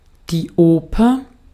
Ääntäminen
Synonyymit Musikdrama Musiktheater Ääntäminen Tuntematon aksentti: IPA: [ˈoːpɐ] Haettu sana löytyi näillä lähdekielillä: saksa Käännös 1. ópera {f} 2. teatro de ópera {m} Artikkeli: die .